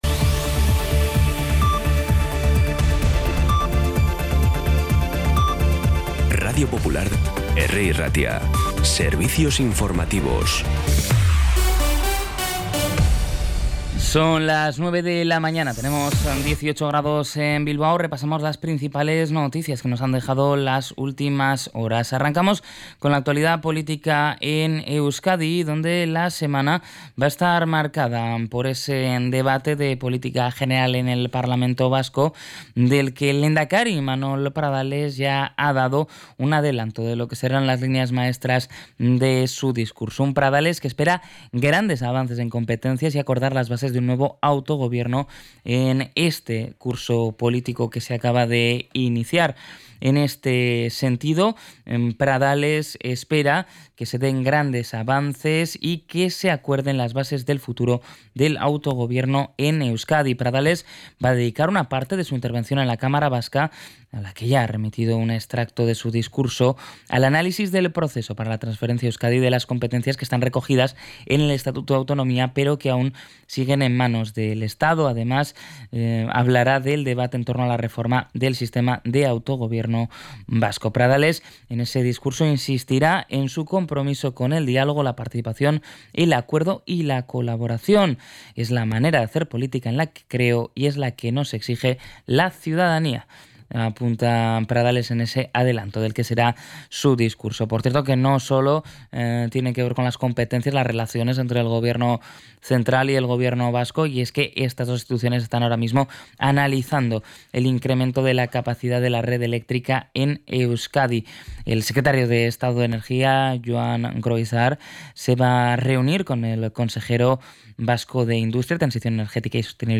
Las noticias de Bilbao y Bizkaia del 16 de septiembre a las 9
Los titulares actualizados con las voces del día.